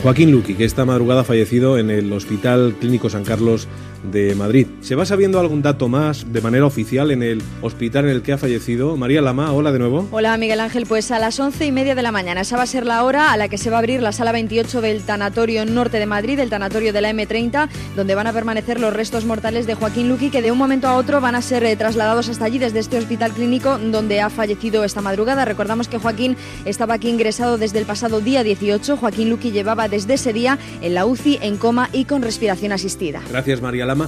Info-entreteniment
Fragment extret del programa "El gran musical de los 40" de Cadena 40 Principales.